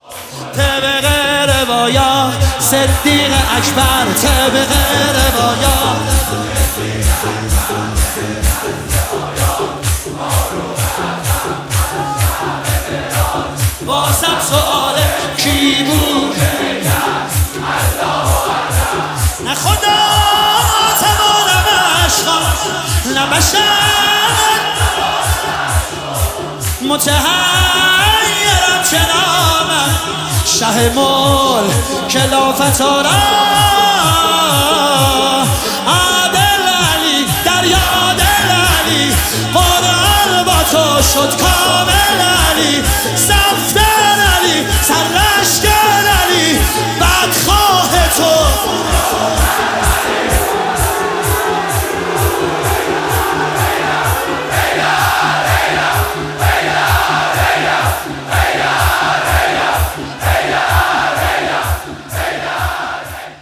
شهادت حضرت ام البنین (س) 1403